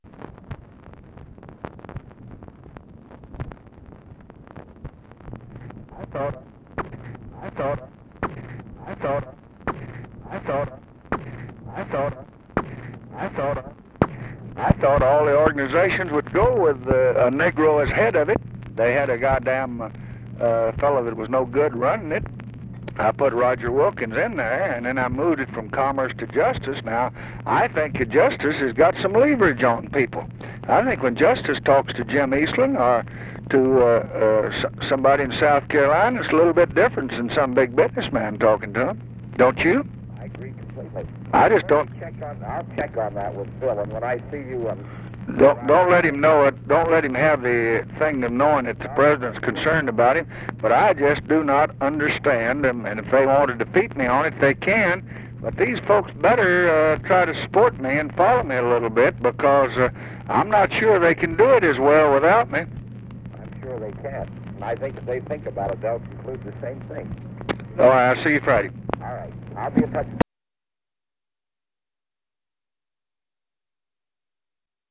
Conversation with WALTER REUTHER, March 7, 1966
Secret White House Tapes